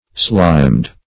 (sl[imac]md); p. pr. & vb. n. Sliming.]